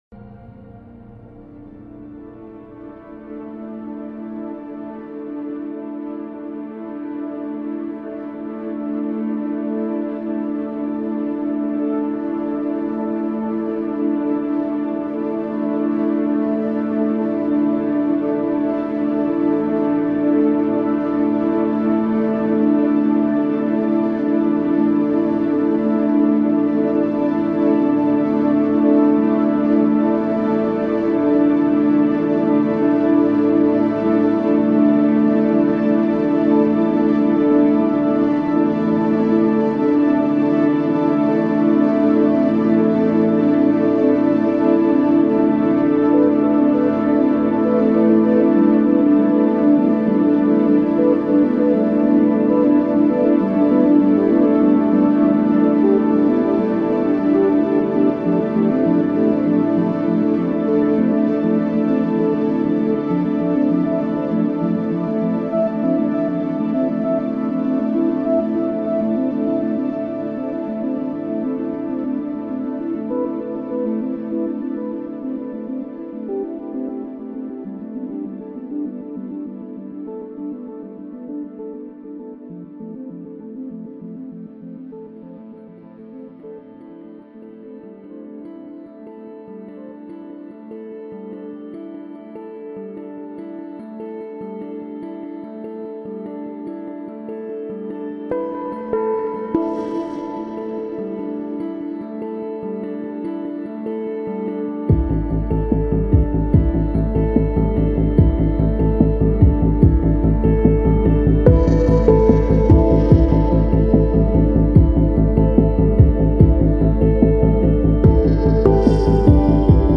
Genre: Downtempo, Ambient, Electronic